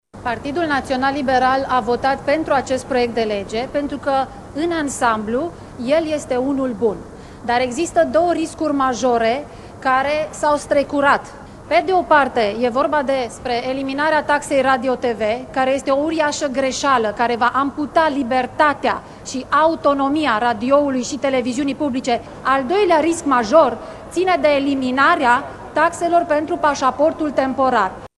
Deputatul PNL, Andreea Paul, a declarat că formațiunea pe care o reprezintă a votat pentru acesastă lege, chiar dacă recunoaște că există riscuri ce pot apărea odată cu adoptarea ei: